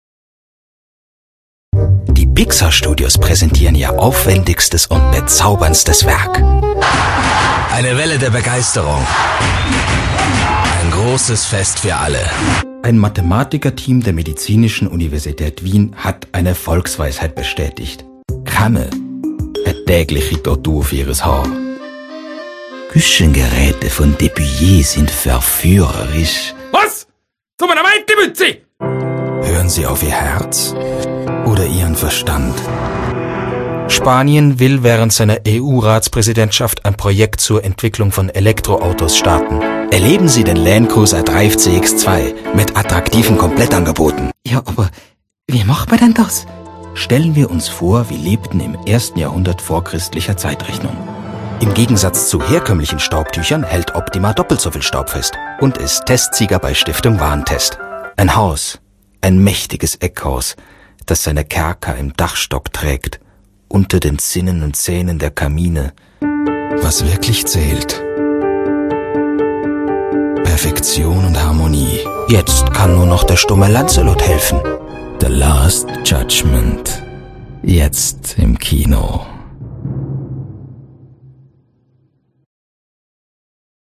Grosse Bandbreite und Wandelbarkeit.
Der Aufnahme- und Regieraum ist zu 100% schalldicht.
Sprechprobe: Werbung (Muttersprache):